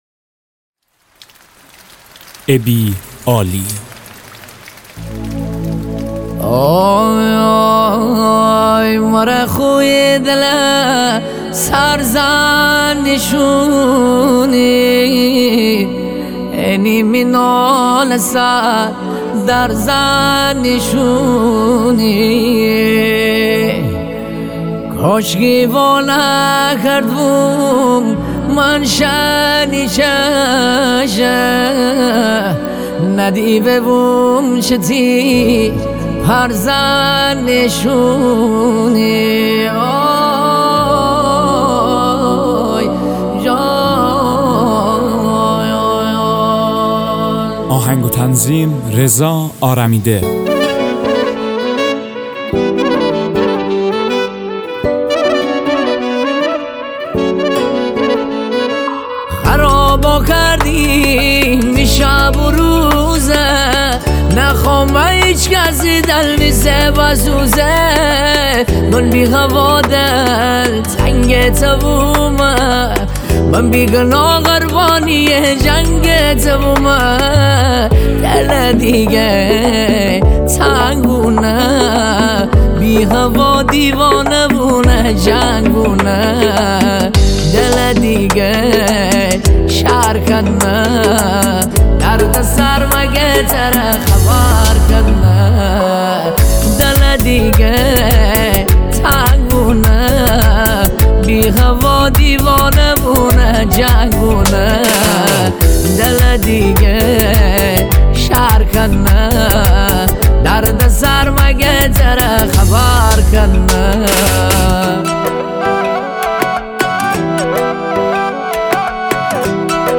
با سبک ریمیکس مازندرانی